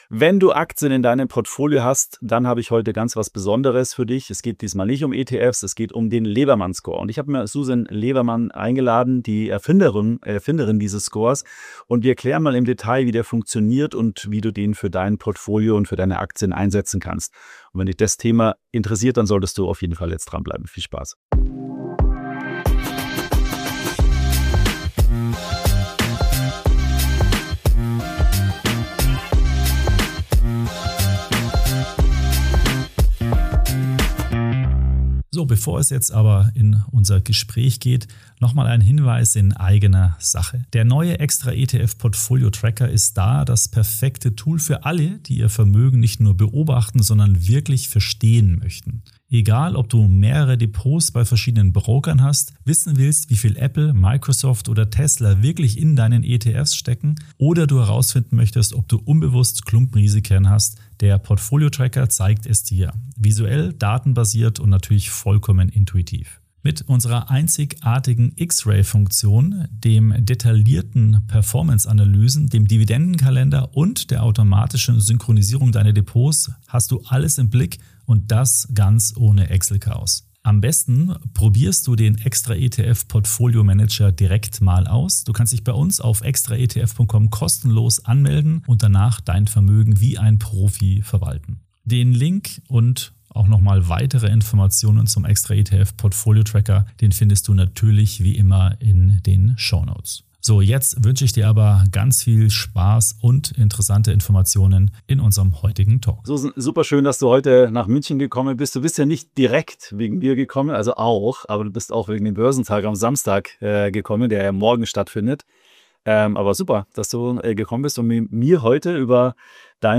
| extraETF Talk ~ extraETF Podcast – Erfolgreiche Geldanlage mit ETFs Podcast